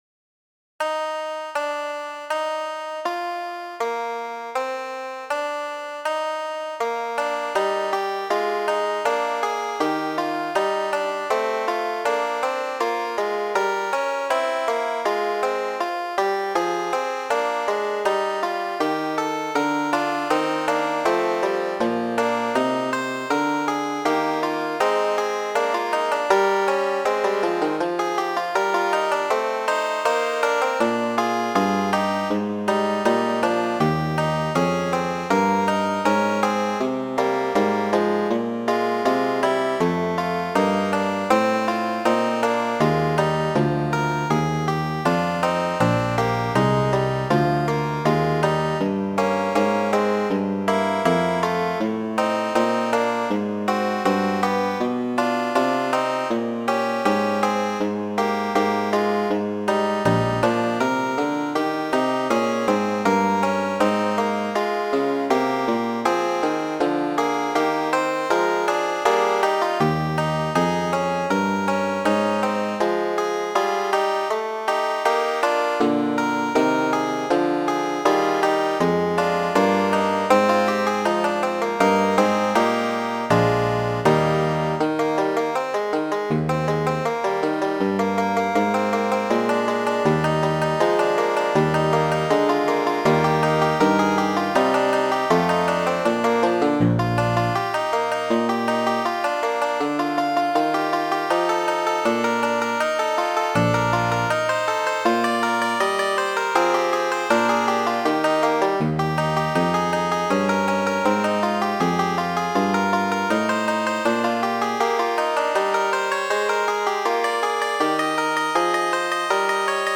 Fuĝo, verko 999 de J. S. Bach por ljuto.